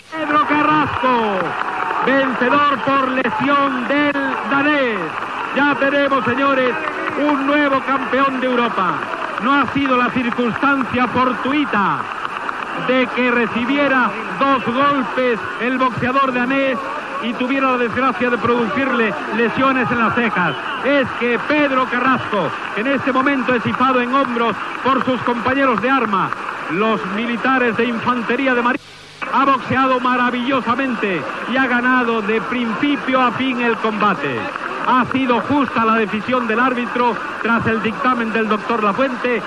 Transmissió de boxa des de Madrid (Espanya). Pedro Carrasco es proclama campió europeu del pes lleuger, en vèncer per superioritat al danès Boerge Krogh
Esportiu